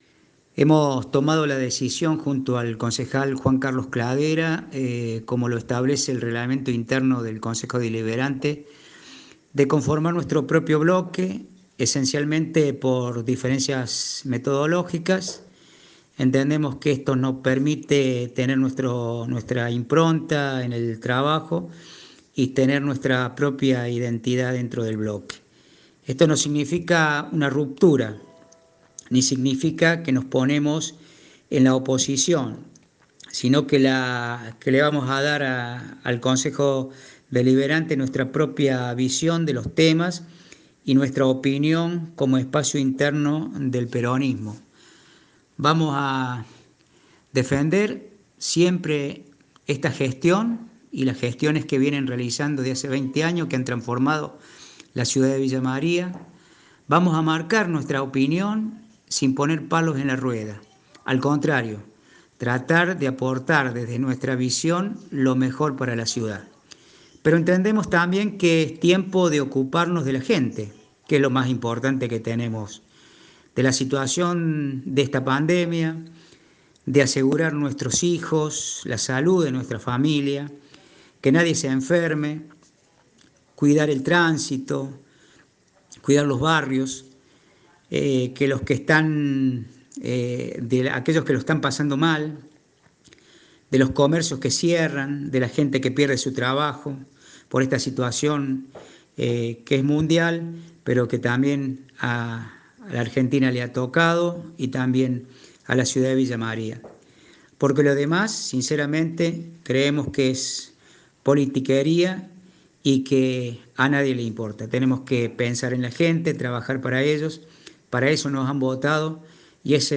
Daniel López, edil del nuevo bloque escindido, habló con Cadena 3 Villa María.